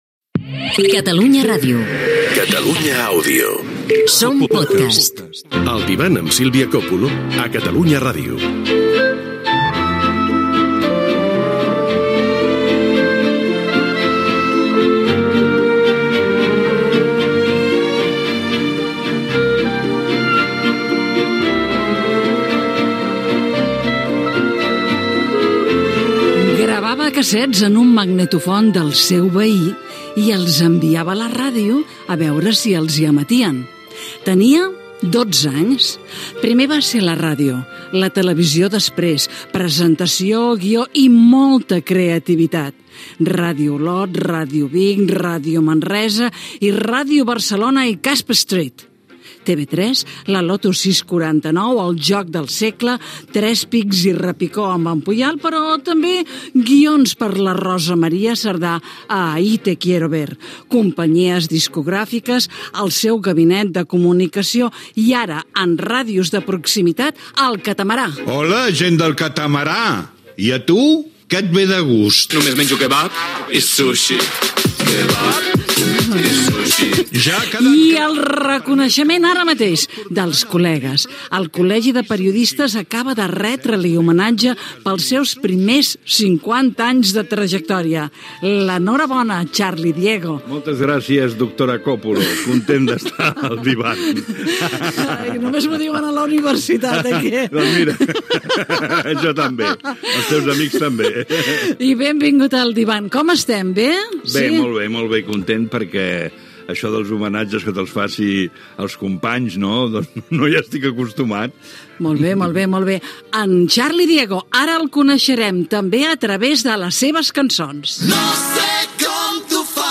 Identificació del programa, presentació i entrevista al presentador Xarli Diego que ha rebut un homenatge del Col·legi de Periodistes de Catalunya i que ha escrit un llibre de memòries
Entreteniment